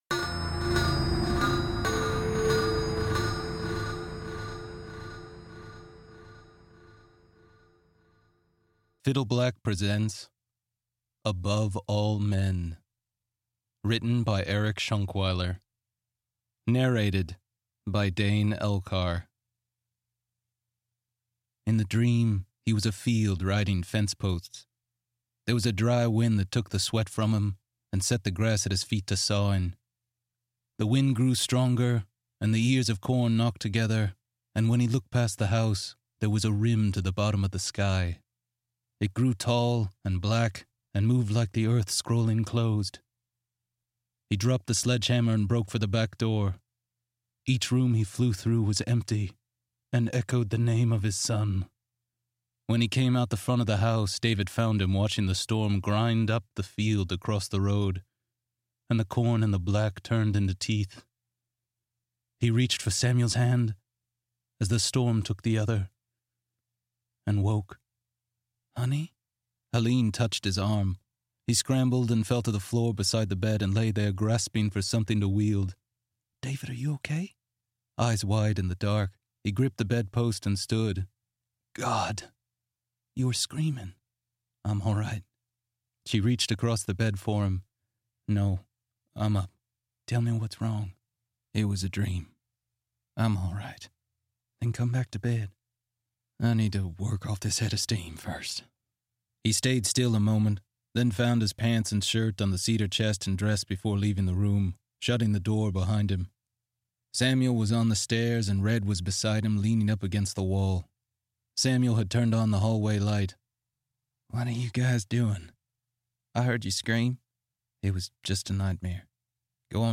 An exclusive cut from chapter 1 of the Above All Men audiobook. Novel by Eric Shonkwiler.